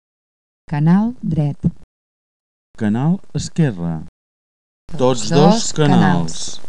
balanç de so.
2. Adoneu-vos que el text «canal dret» se sent només per l'altaveu o auricular dret, el text «canal esquerre» per l'altaveu o auricular esquerre, i el text «tots dos canals» per ambdós altaveus o auriculars.